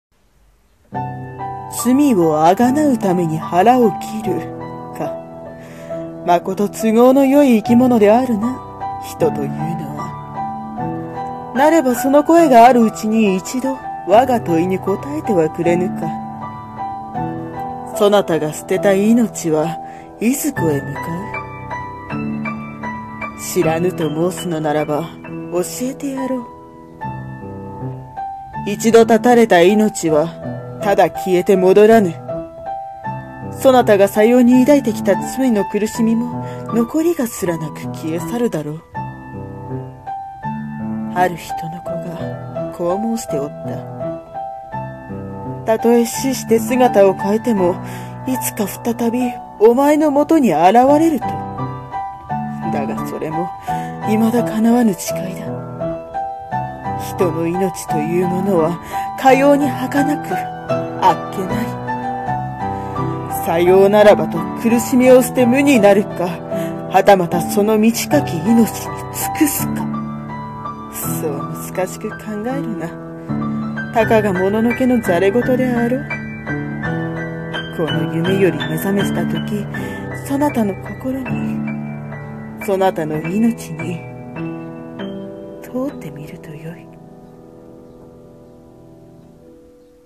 命に問う【一人声劇
ピアノ